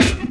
Snare 23.wav